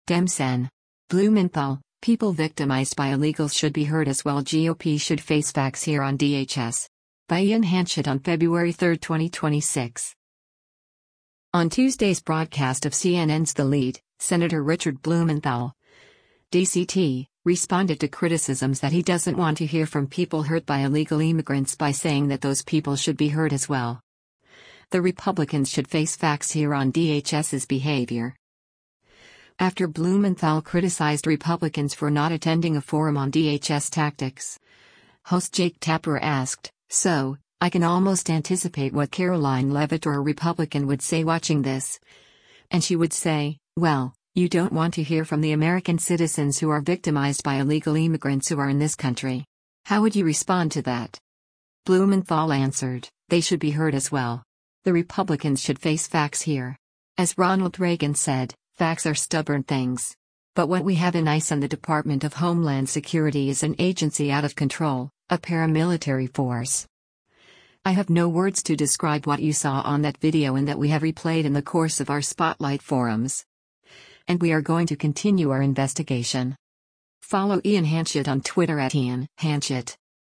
On Tuesday’s broadcast of CNN’s “The Lead,” Sen. Richard Blumenthal (D-CT) responded to criticisms that he doesn’t want to hear from people hurt by illegal immigrants by saying that those people “should be heard as well. The Republicans should face facts here” on DHS’s behavior.